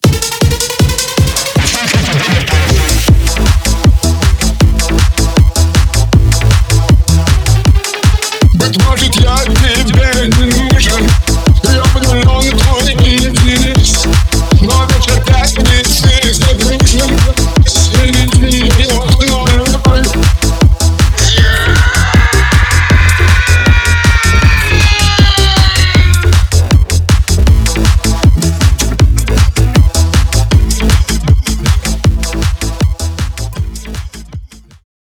По гармонии и мелодии очень попахивает 80-ми (Динамик, Альфа и пр.)